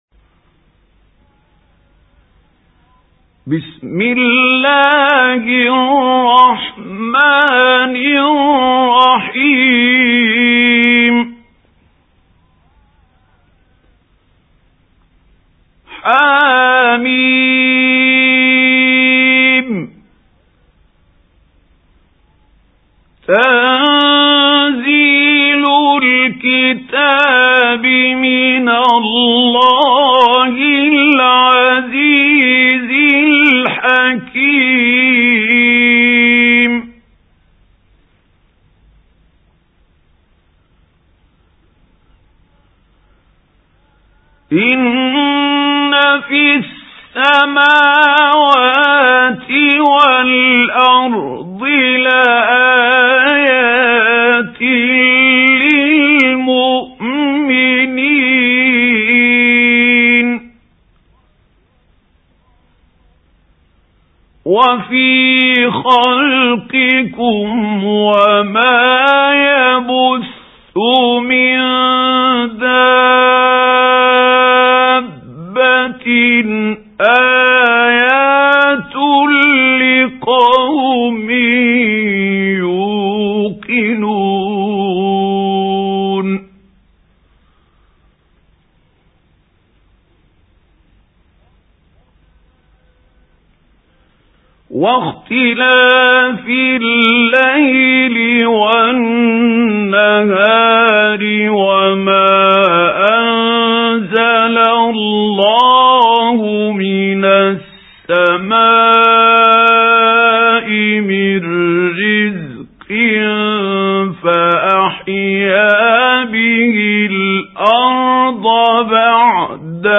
سُورَةُ الجَاثِيَةِ بصوت الشيخ محمود خليل الحصري